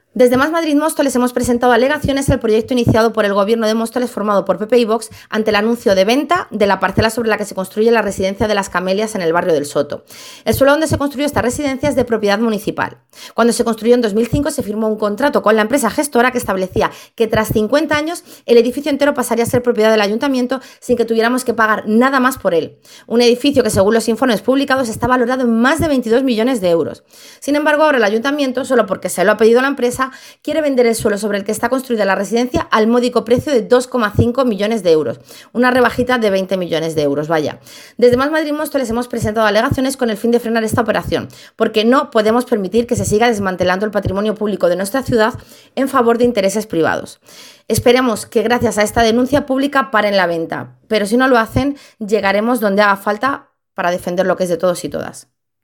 Ana Tejero, concejala de Más Madrid Móstoles ha declarado: «El artículo 103 de la Constitución Española consagra a las Administraciones Públicas a velar por el interés general, de producirse finalmente esta operación vulneraría el interés general del Ayuntamiento de Móstoles y, por tanto, de todas las vecinas y vecinos de Móstoles, ya que perderíamos millones de euros de forma irreparable.
declaraciones-ana-tejero-residencia-camelias.mp3